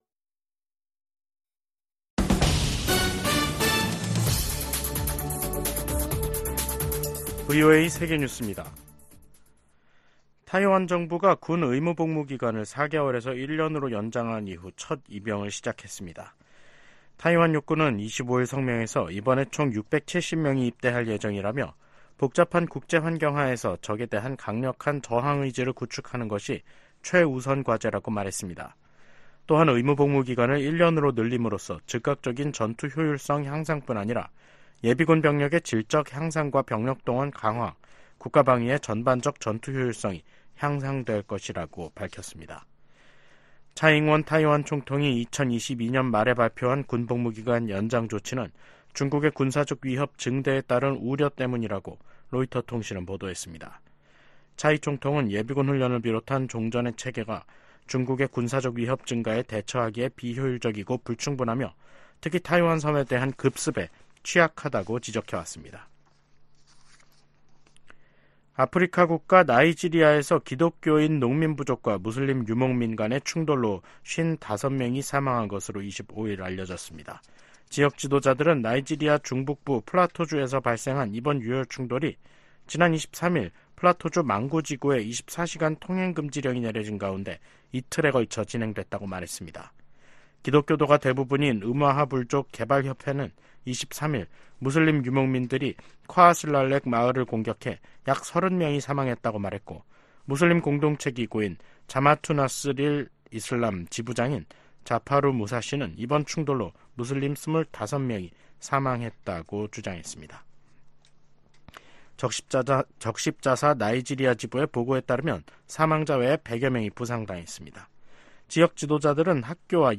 VOA 한국어 간판 뉴스 프로그램 '뉴스 투데이', 2024년 1월 26일 3부 방송입니다. 제네바 군축회의에서 미국과 한국 등이 북한의 대러시아 무기 지원을 규탄했습니다. 미 국방부는 북한의 대러시아 무기 지원이 우크라이나 침략 전쟁을 장기화한다고 비판했습니다. 김정은 북한 국무위원장은 지방 민생이 생필품 조차 구하기 어려운 수준이라면서, 심각한 정치적 문제라고 간부들을 질타했습니다.